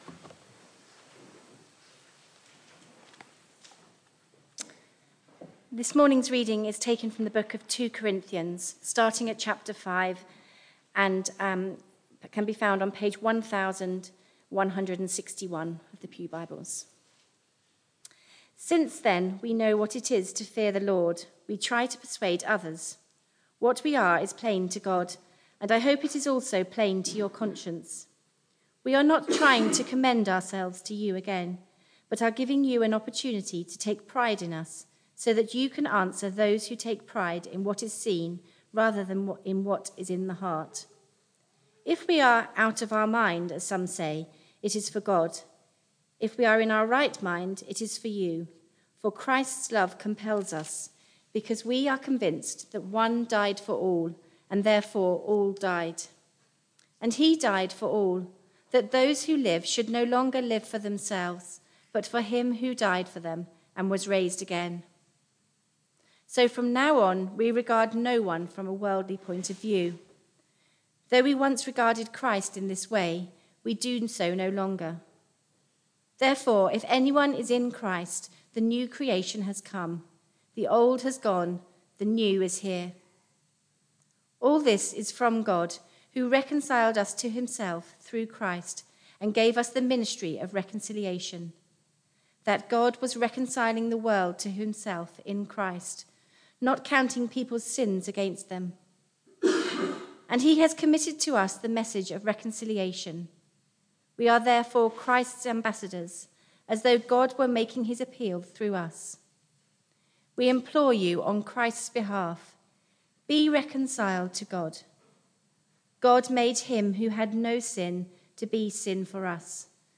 Media for Barkham Morning Service on Sun 05th Nov 2023 10:00
Theme: Sermon